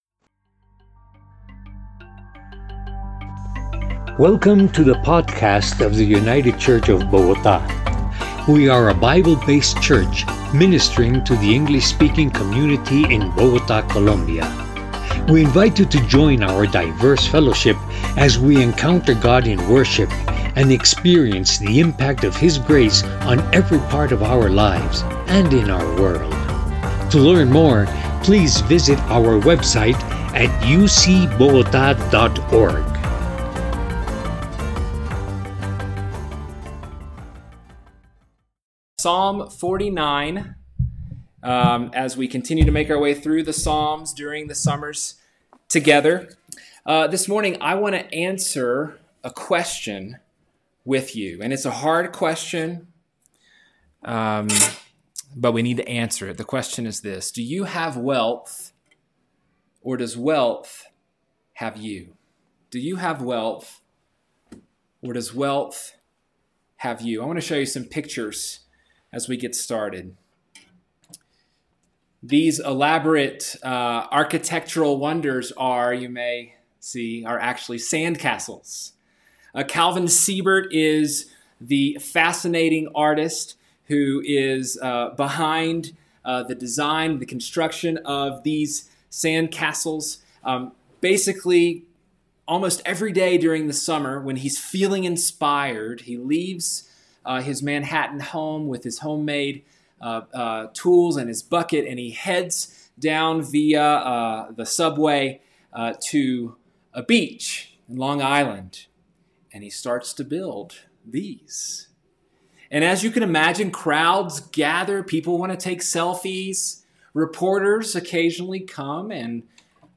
By United Church of Bogotá | 2020-08-02T14:53:56-05:00 August 2nd, 2020 | Categories: Sermons | Tags: Summer Psalms | Comments Off on Castles Made of Sand Share This Story, Choose Your Platform!